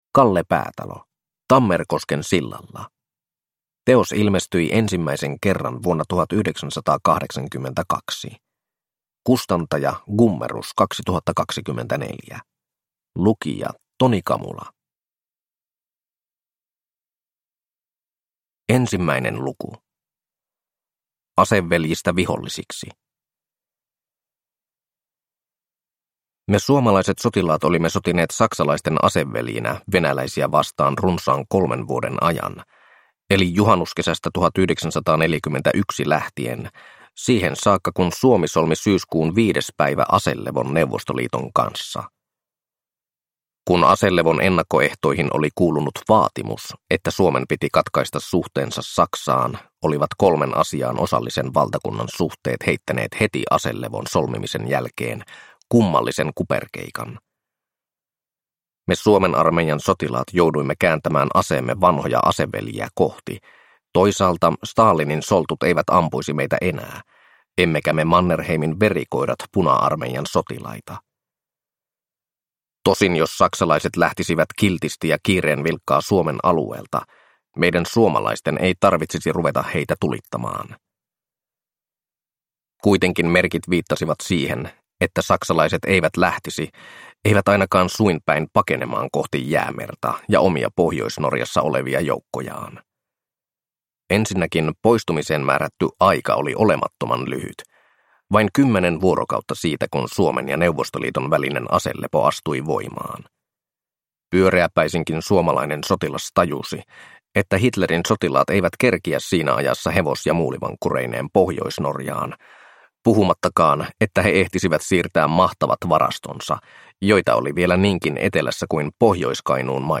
Tammerkosken sillalla (ljudbok) av Kalle Päätalo